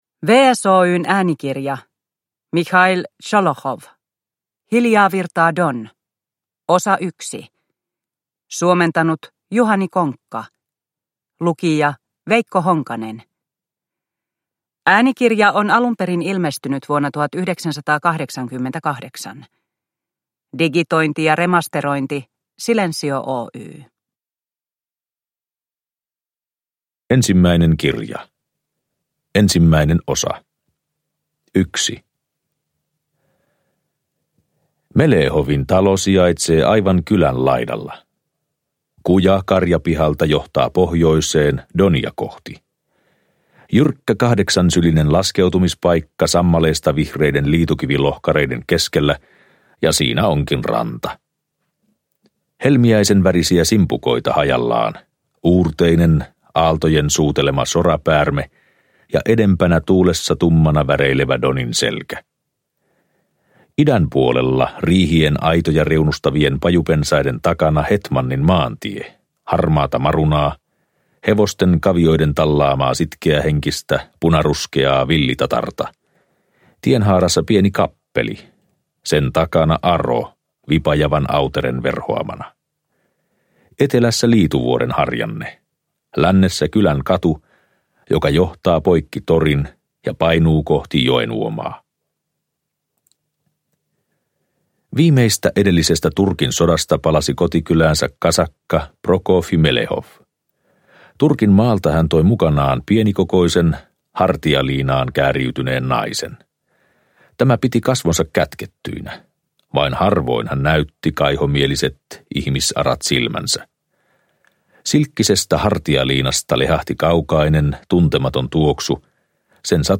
Hiljaa virtaa Don I – Ljudbok – Laddas ner
Venäläiseen kirjallisuuteen erikoistuneen Juhani Konkan suomennos on julkaistu äänikirjana vuosina 1988–89.